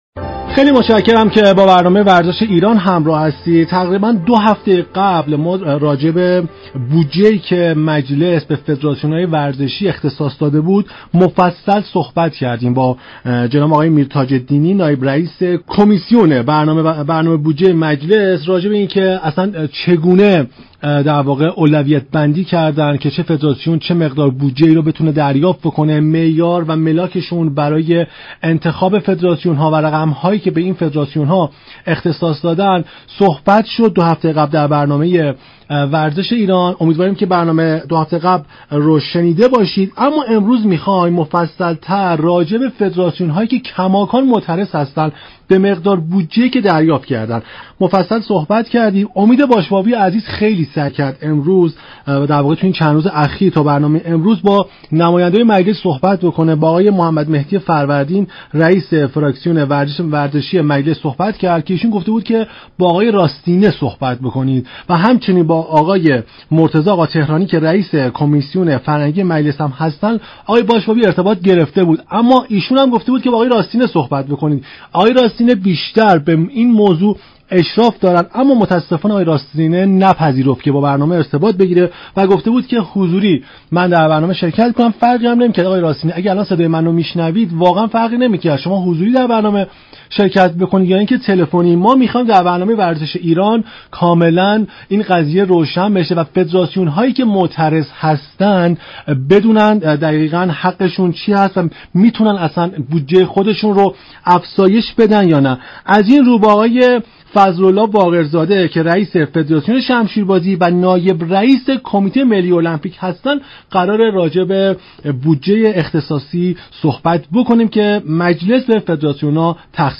پخش مناظره ای جنجالی در برنامه ورزش ایران
برنامه «ورزش ایران» رادیو ایران با پخش مناظره جنجالی احمد راستینه نماینده مجلس و فضل الله باقرزاده نائب رئیس كمیته ملی المپیك پیرامون بودجه فدراسیون های ورزشی مهمان خانه های مردم شد.